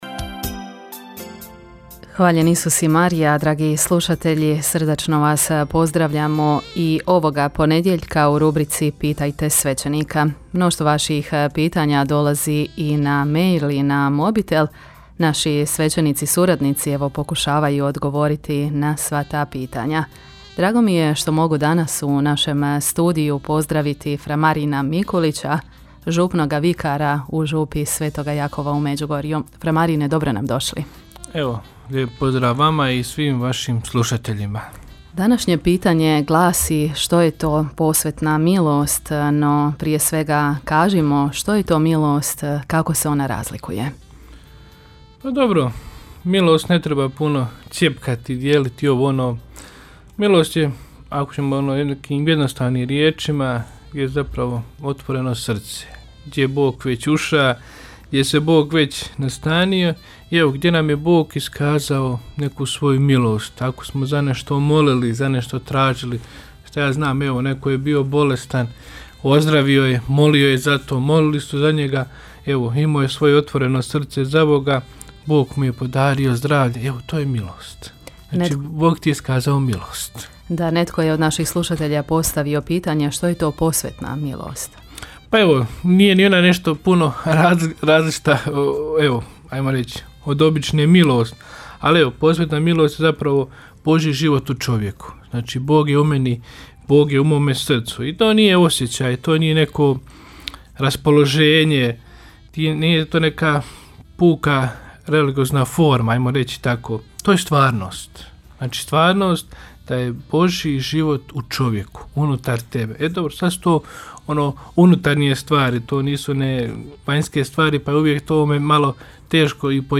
Rubrika ‘Pitajte svećenika’ u programu Radiopostaje Mir Međugorje je ponedjeljkom od 8 sati i 20 minuta, te u reprizi ponedjeljkom navečer u 20 sati i 15 minuta. U njoj na pitanja slušatelja odgovaraju svećenici, suradnici Radiopostaje Mir Međugorje.